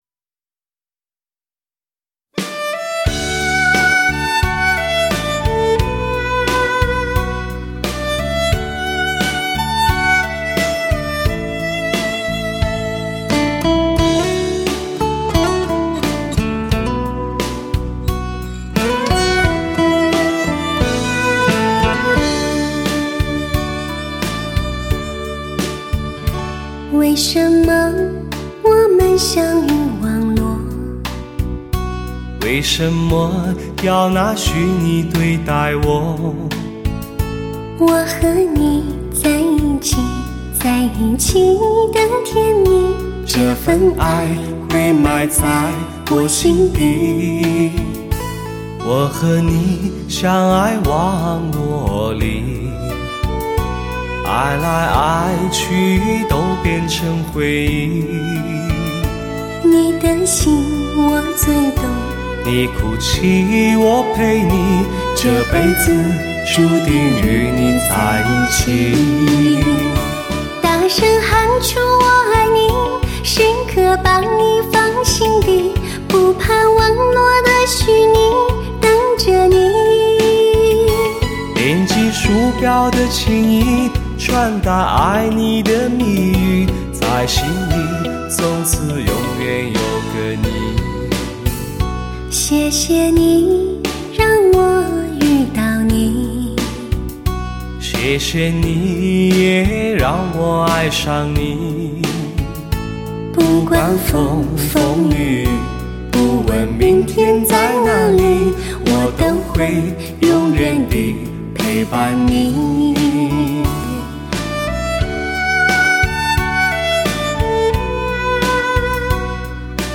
情歌对唱
感性、迷人的唱腔伴随着生动活泼的鼓击乐